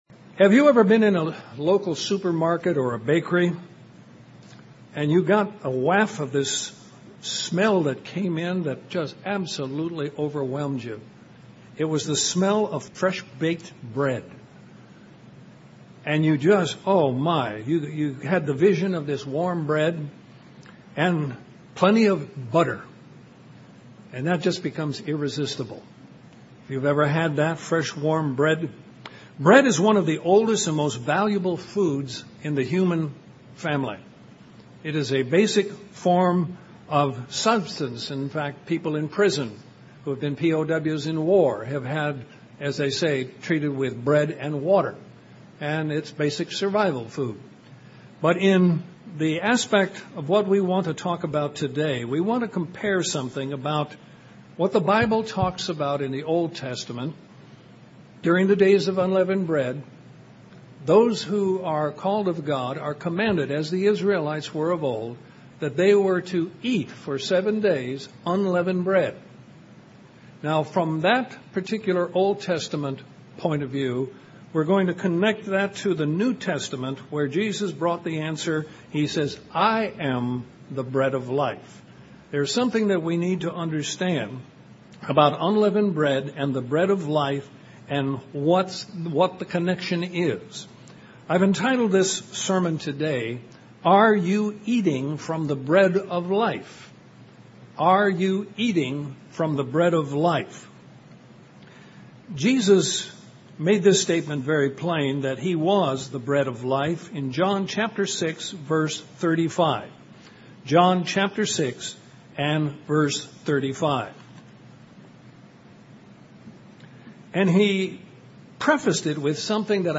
What is your daily spiritual diet? This sermon encourages us to eat daily of the word of God so that we maintain a close relationship with God the father and Jesus Christ.